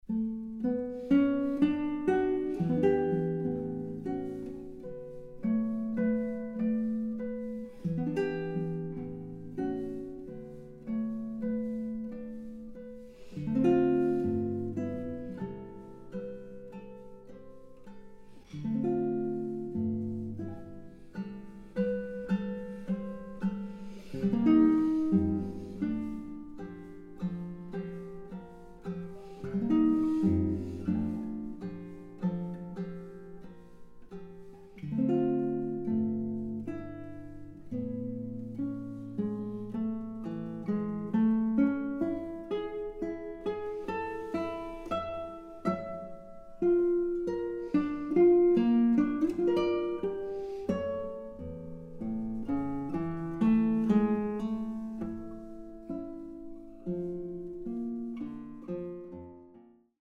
Guitar
richly colored and precisely articulated guitar sound